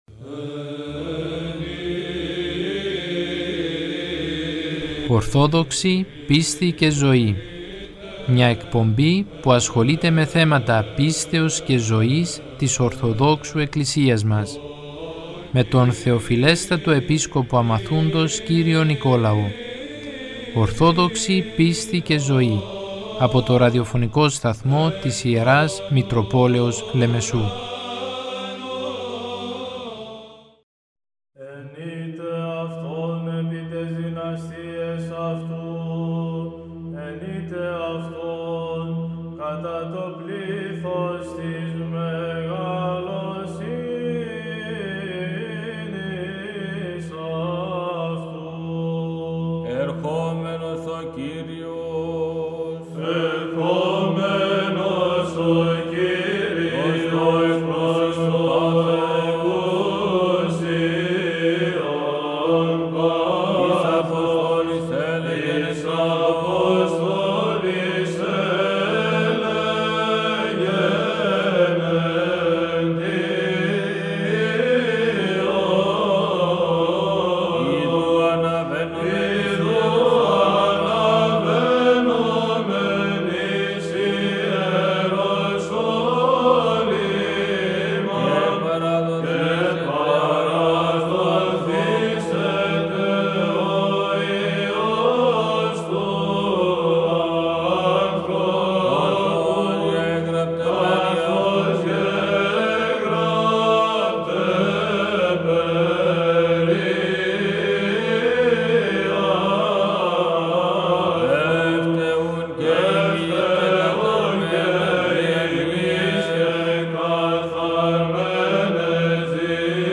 «ΟΡΘΟΔΟΞΗ ΠΙΣΤΗ ΚΑΙ ΖΩΗ» – Μία εκπομπή του Θεοφιλεστάτου Επισκόπου Αμαθούντος, κ. Νικολάου, που ασχολείται με θέματα Πίστεως και Ζωής της Ορθοδόξου Εκκλησίας μας. Μπορείτε να την παρακολουθείτε, κάθε Τρίτη στις 12.00 το μεσημέρι από τον Ραδιοφωνικό Σταθμό της Ιεράς Μητροπόλεως Λεμεσού.